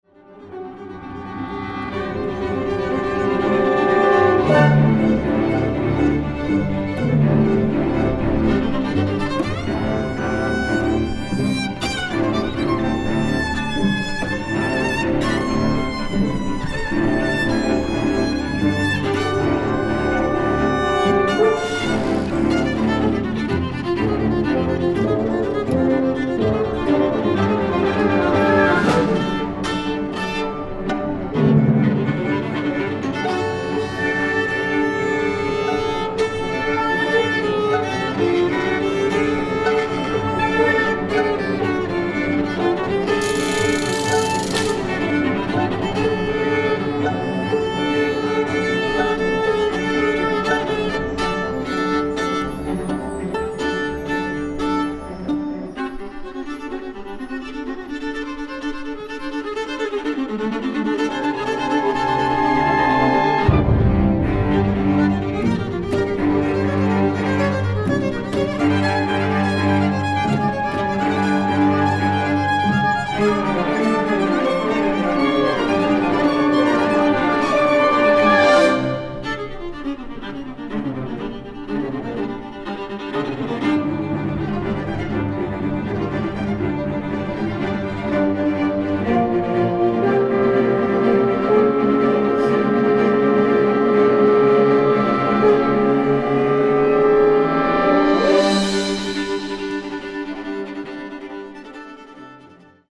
viola & chamber orchestra
(2222 2 hrn 2 tpt 2 prc strings)